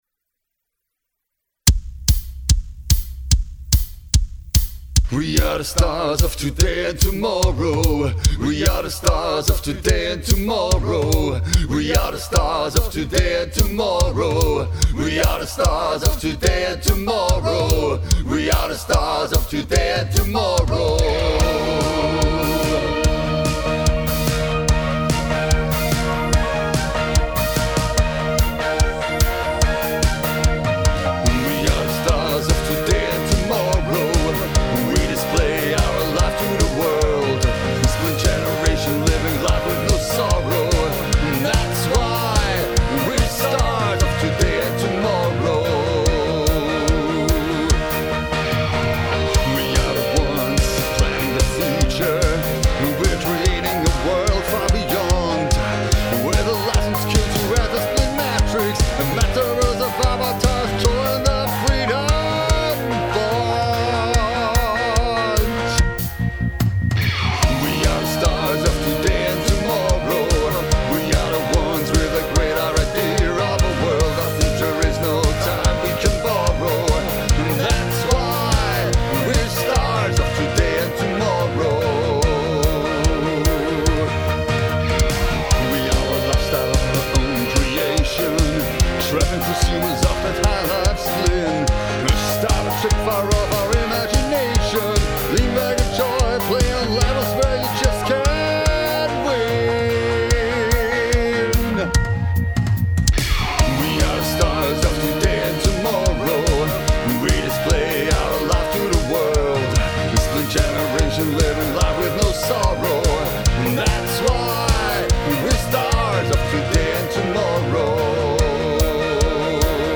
WE ARE THE STARS OF TODAY AND TOMORROW - HiLife Spleen version 2022 - PB Demo und Gesangs Demo V2.mp3